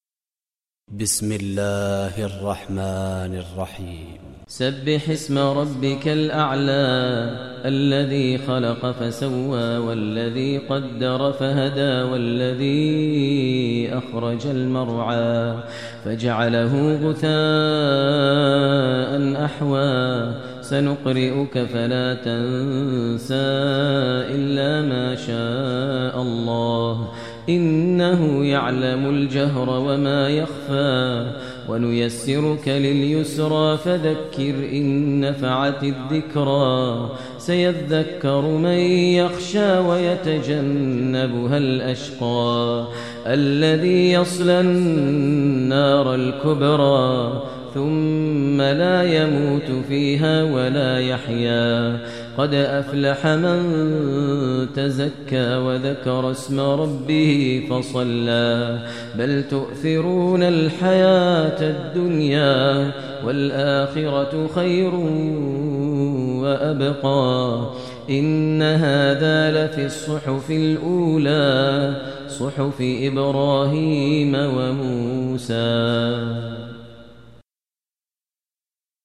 Download Surah Ala Tilawat Maher al Mueaqly